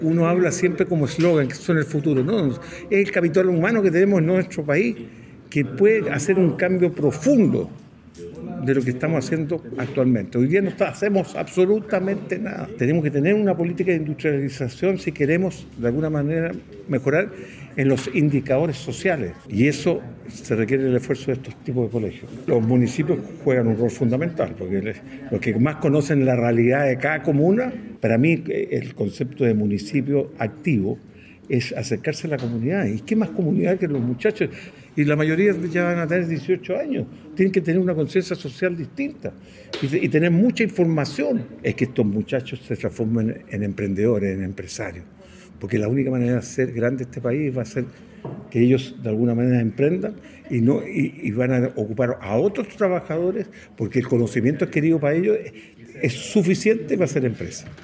El empresario destacó la importancia de trabajar con los jóvenes, ya que ellos son parte del futuro de nuestro país, donde los distintos municipios son parte activa de la comunidad.